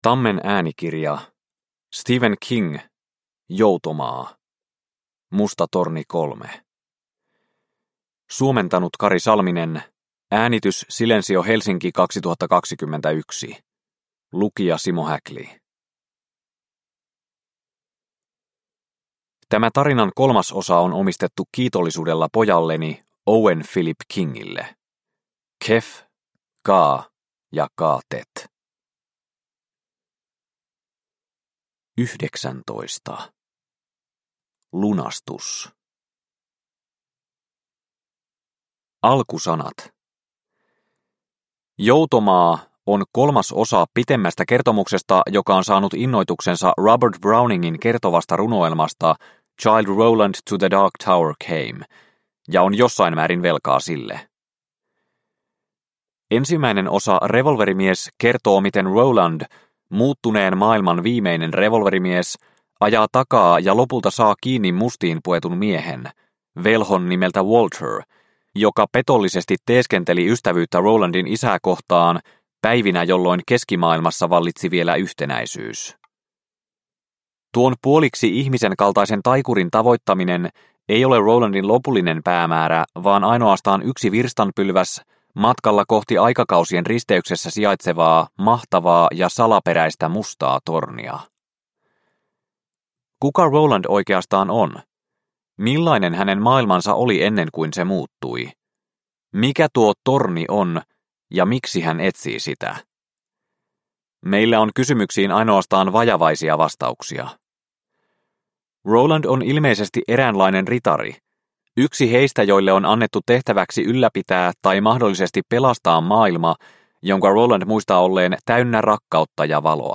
Joutomaa – Ljudbok – Laddas ner